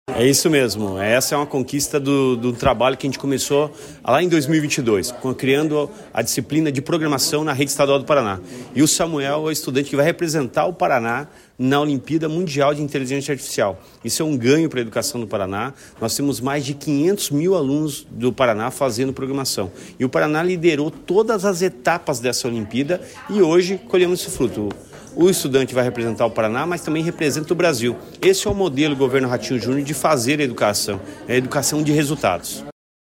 Sonora do secretário da Educação, Roni Miranda, sobre estudante da rede estadual que participará da Olimpíada Internacional de Inteligência Artificial | Governo do Estado do Paraná